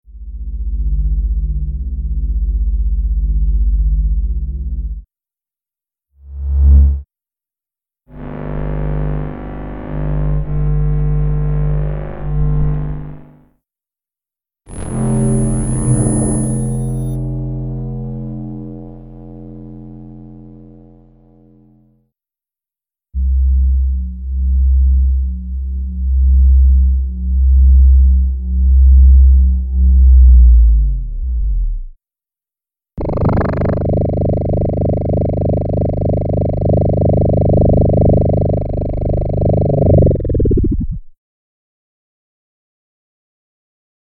Звуки магнита
Еще один электромагнит (сборник звуков для монтажа)